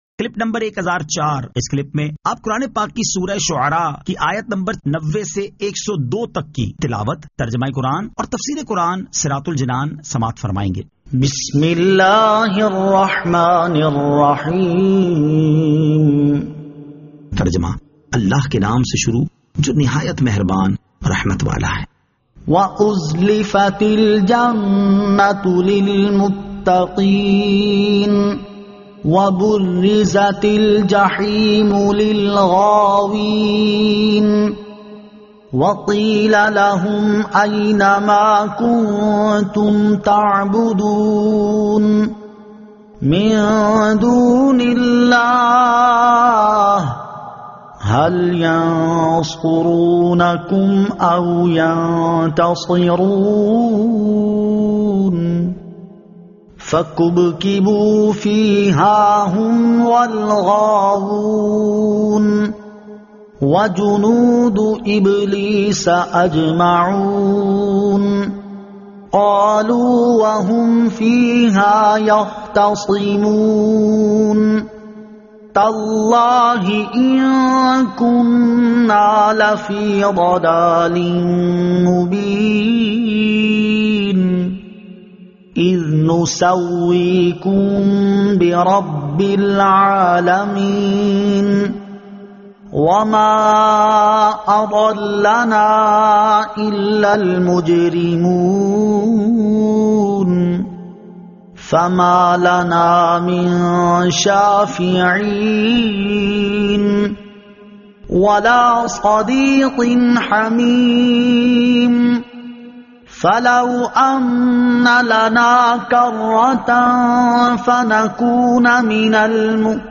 Surah Ash-Shu'ara 90 To 102 Tilawat , Tarjama , Tafseer